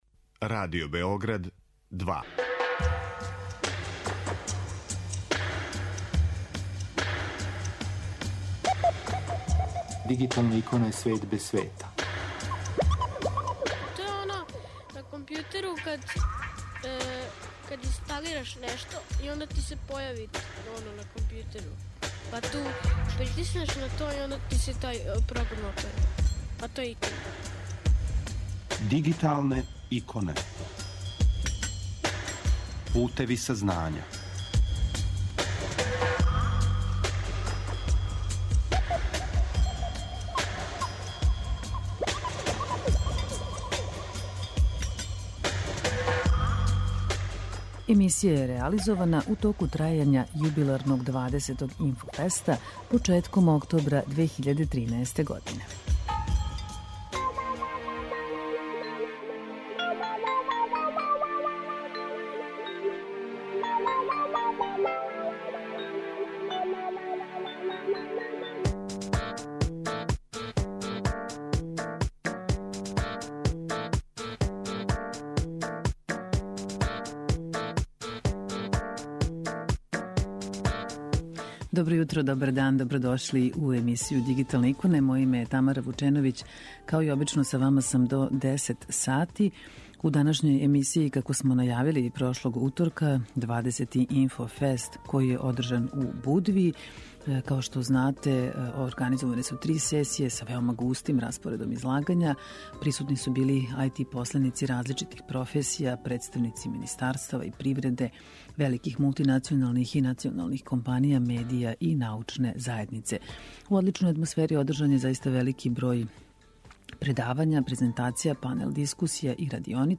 Ова емисија је реализована у току трајања 20. Инфофеста, jедног од највећих информатичких скупова у региону, који је одржан од 29. септембра до 5. октобра прошле године у Будви.